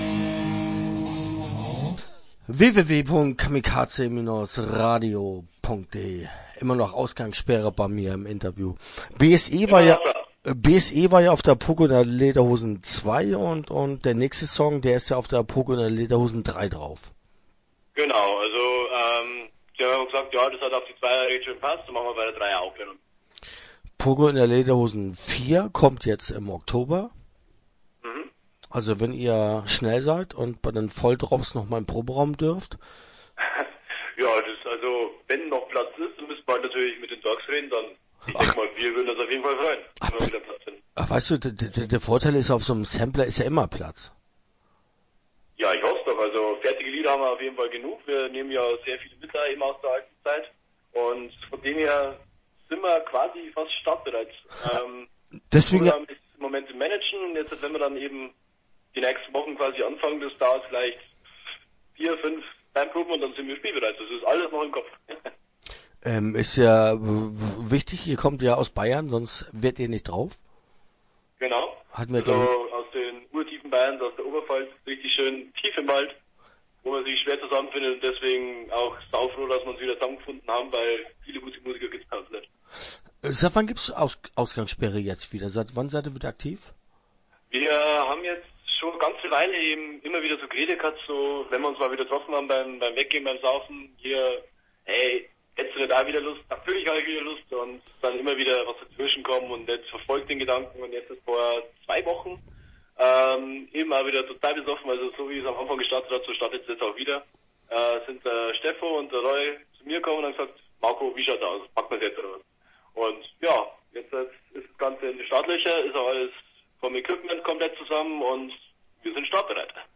Ausgangssperre - Interview Teil 1 (6:29)